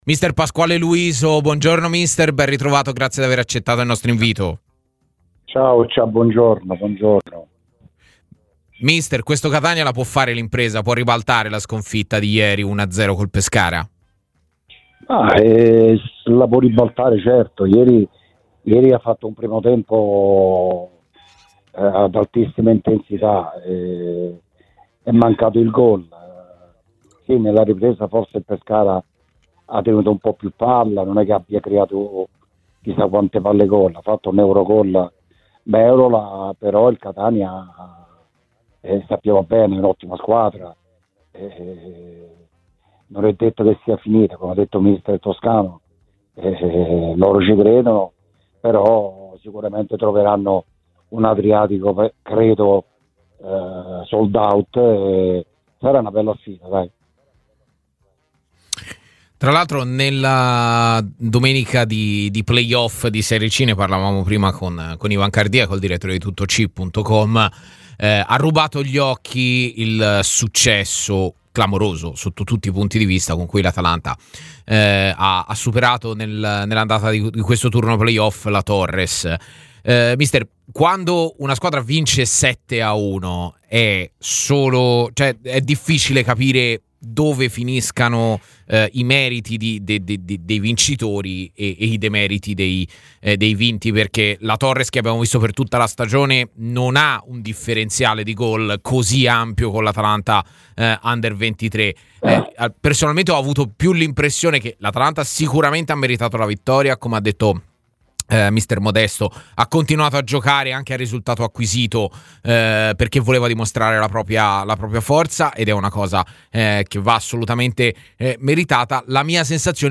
Ospite dei microfoni di TMW Radio all'interno della trasmissione 'A Tutta C,